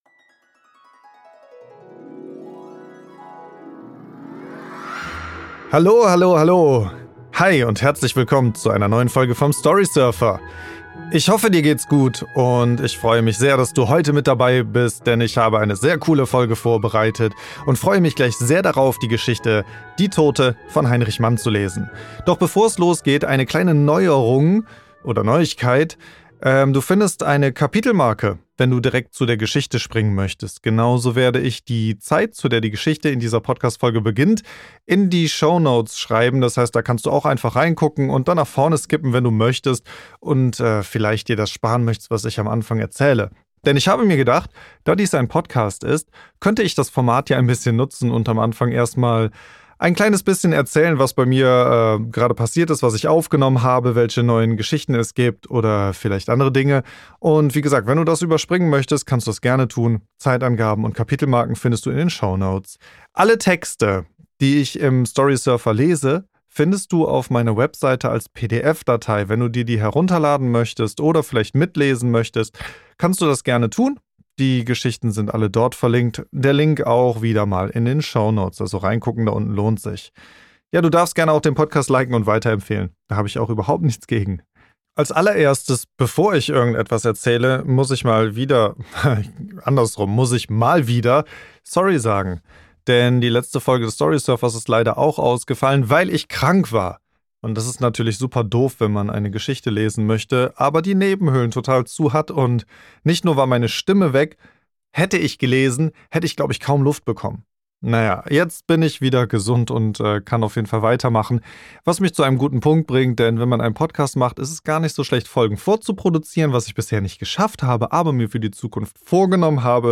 Die besten Kurzgeschichten berühmter Autor*innen vorgelesen - ein Hörbuch in Podcast-Form.